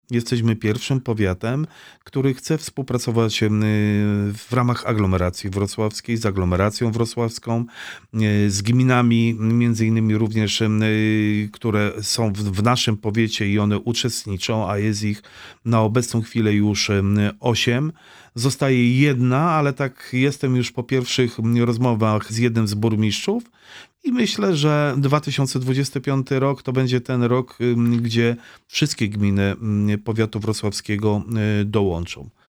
W studiu Radia Rodzina Włodzimierz Chlebosz